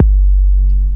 KICK178.wav